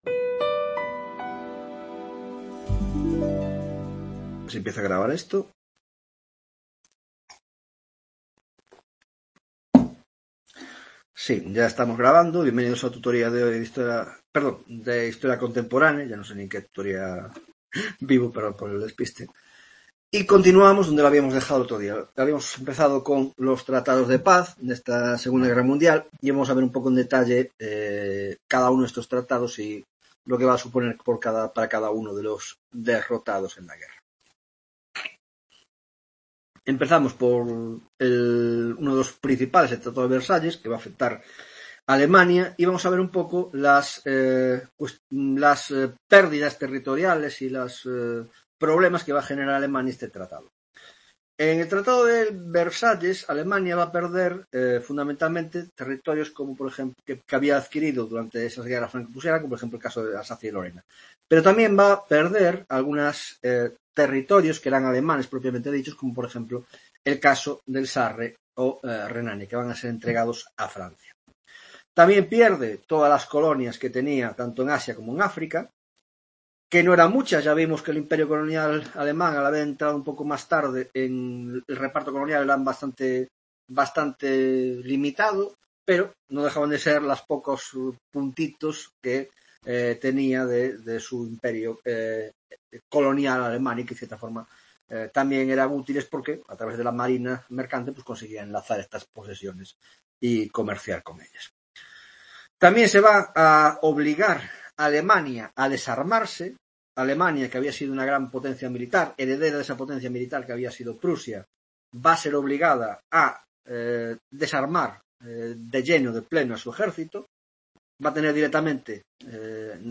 17ª tutoria de Historia Contemporánea - La I Guerra Mundial (3ª parte) - 1) Los Tratos de Paz; 2) La Sociedad de Naciones